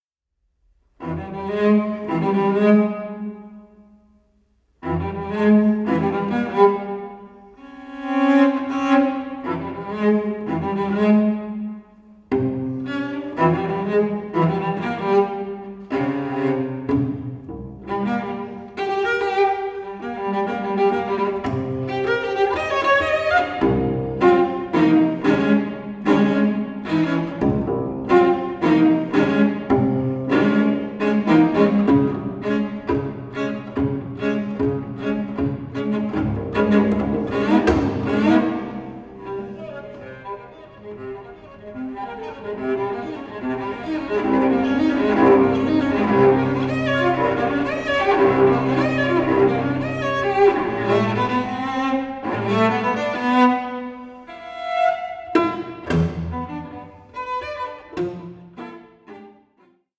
solo cello